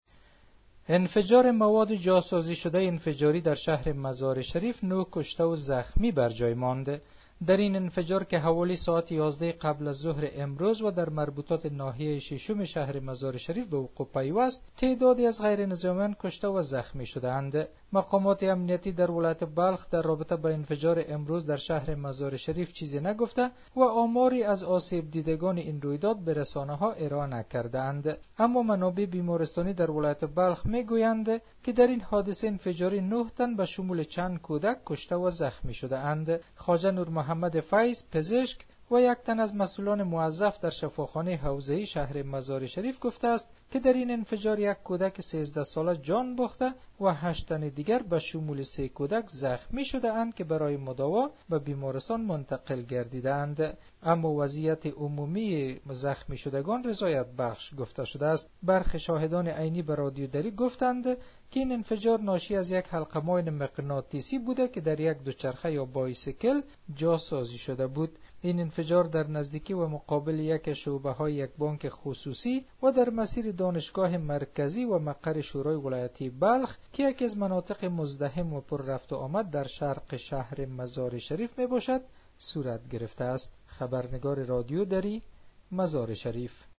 جزئیات بیشتر این خبر در گزارش